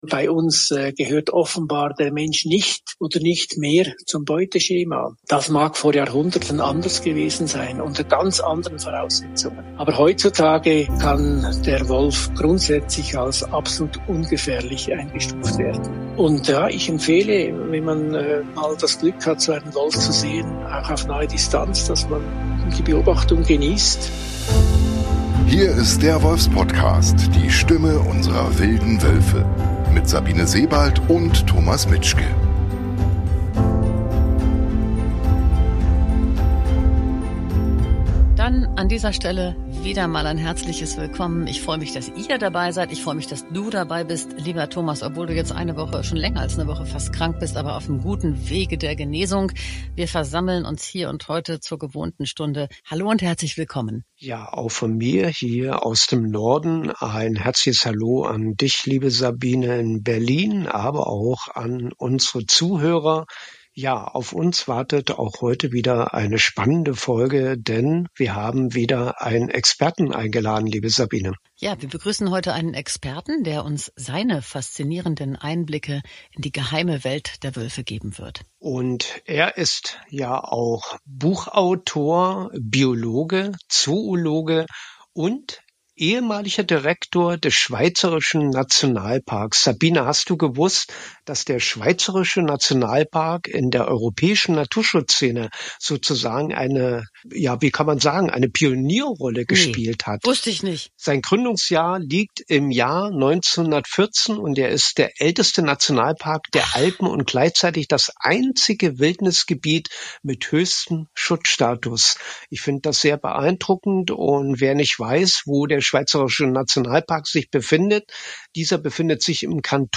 Über dieses Buch gibt es viel zu erzählen. Heraus gekommen ist dabei ein Gespräch, das nicht nur informativ und sehr persönlich, sondern auch zutiefst bewegend ist.